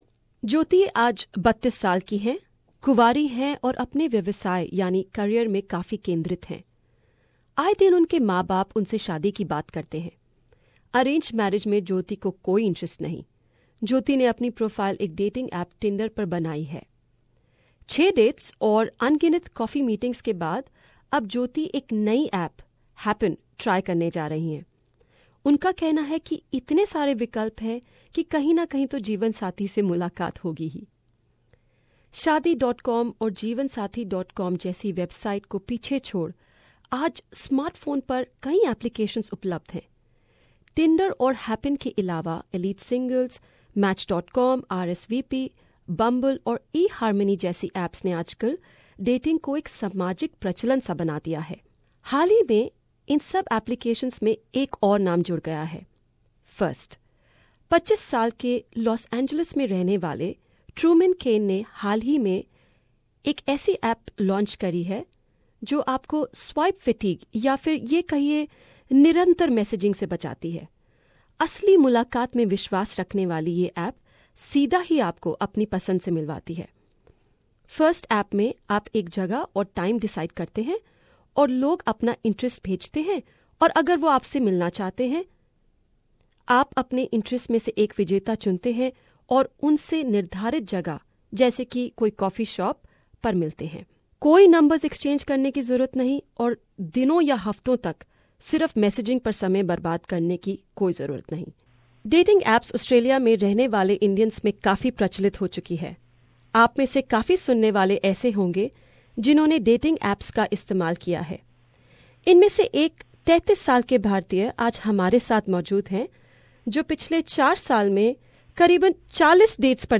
With so many dating apps available, has it become easier to find a date... We round up a few apps and speak with a 33-year old Indian man who has been to 40 dates in 4 years.